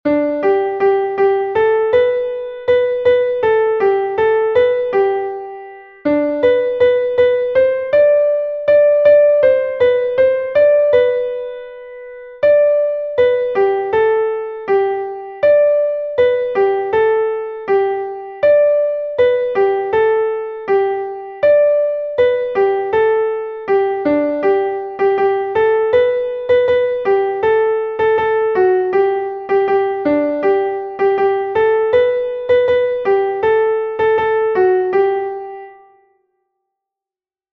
„Ich bin ein Musikante“ ist ein volkstümliches Kinderlied, dessen Herkunft sowohl im Text als auch in der Melodie tief in der Tradition verankert ist.
Einstimmige Melodie im Violinschlüssel, G-Dur, 2/4-Takt, mit der 1. Strophe des Liedtextes.
ich-bin-ein-musikante_klavier_melodiemeister.mp3